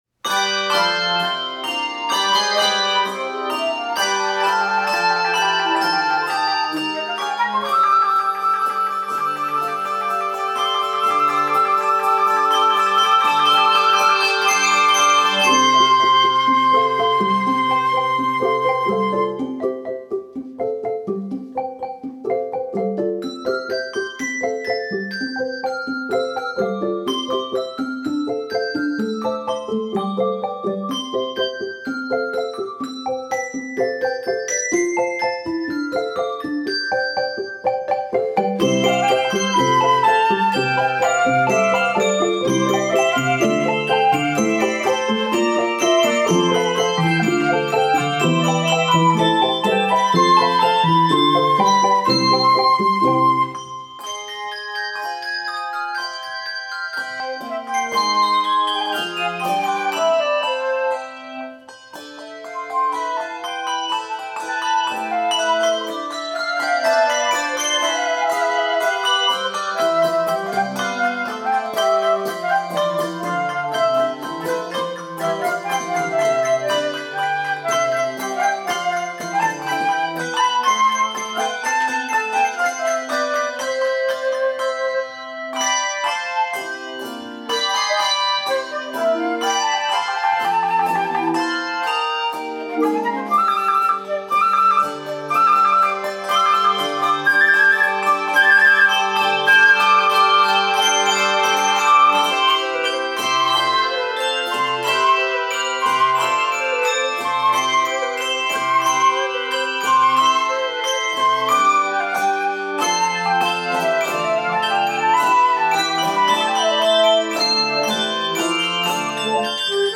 Voicing: Handbells 3-6 Octave